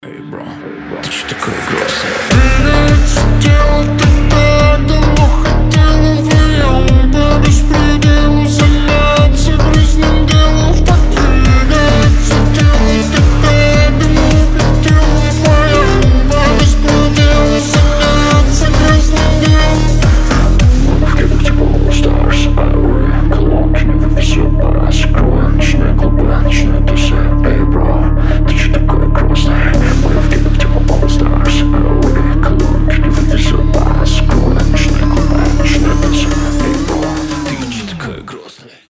• Качество: 320, Stereo
басы
house